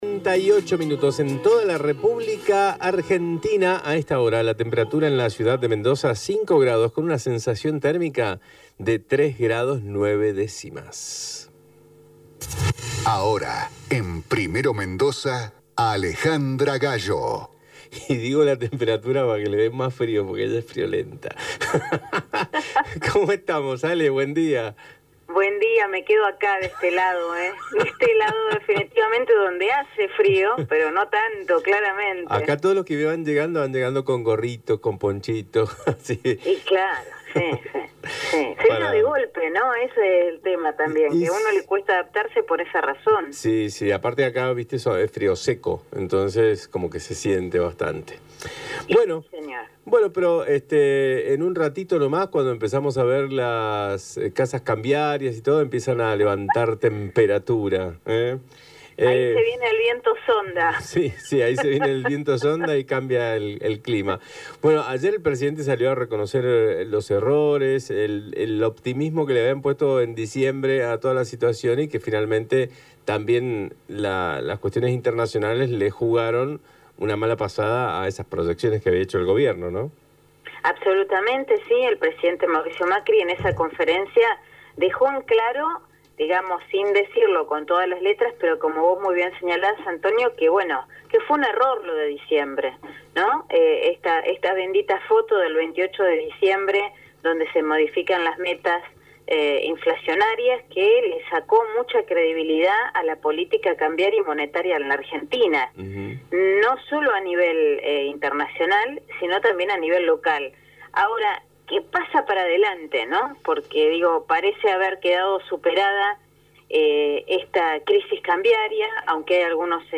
por Radio Mitre Mendoza, la emisora líder de la región Cuyana.